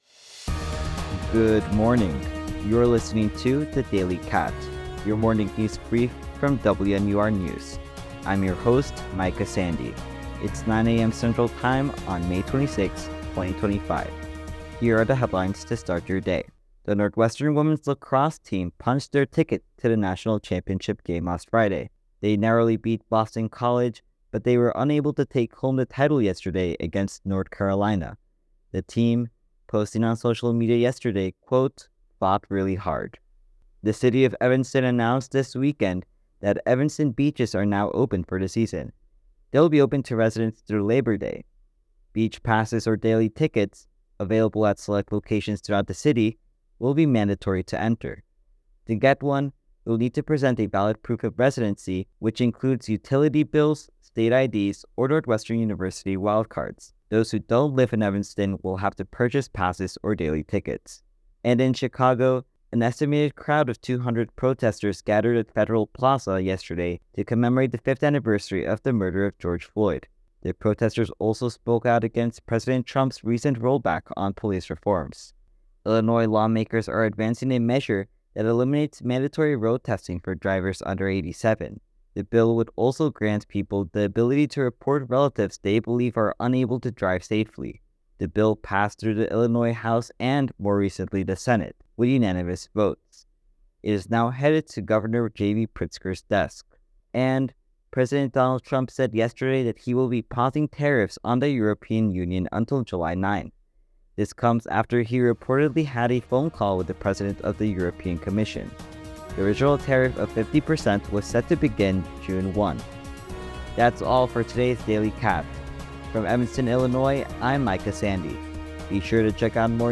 May 26, 2025: Northwestern women’s lacrosse, Evanston beaches, George Floyd commemoration, driving bill and European Union tariffs. WNUR News broadcasts live at 6 pm CST on Mondays, Wednesdays, and Fridays on WNUR 89.3 FM.